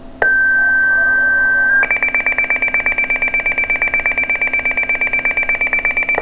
Dispatch Sounds
Second Tones With Pager Activation (6.25 sec - 268 Kb)   (Second Calls and Additional Manpower Calls)